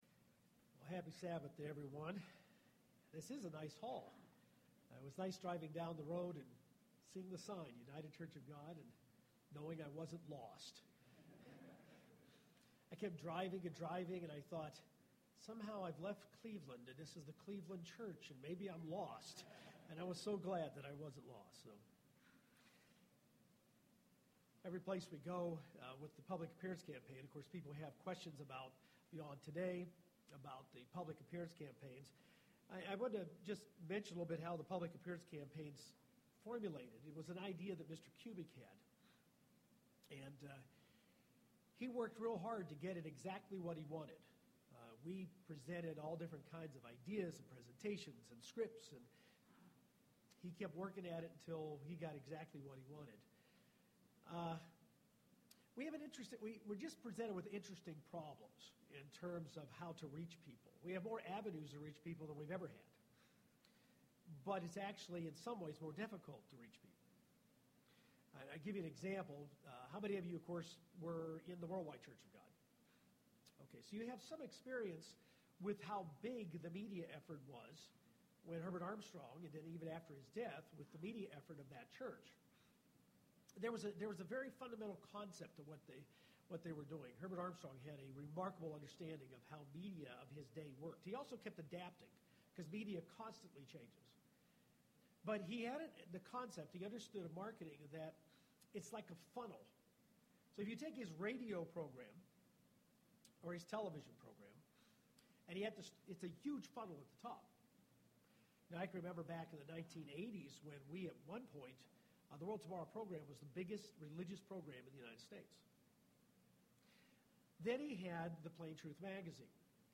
UCG Sermon Faith Transcript This transcript was generated by AI and may contain errors.